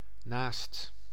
Ääntäminen
IPA: /naːst/